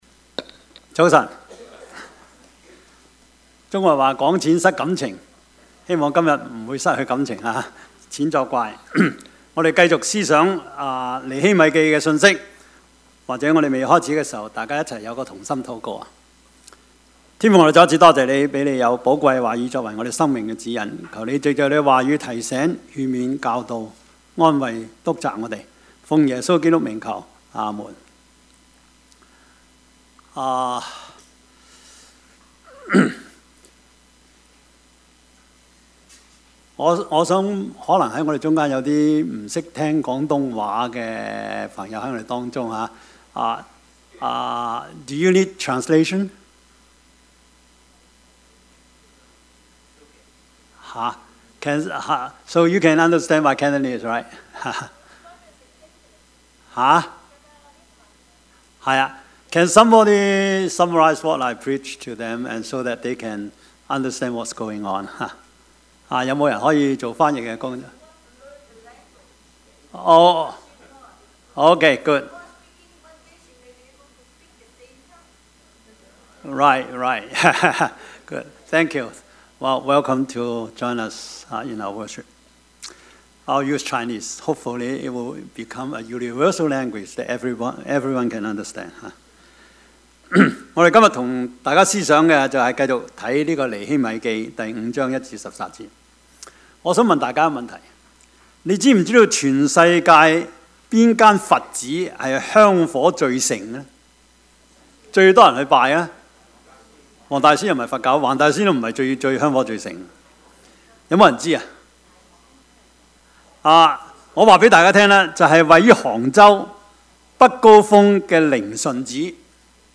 Service Type: 主日崇拜
Topics: 主日證道 « 基督教倫理學(二) 負主的軛 »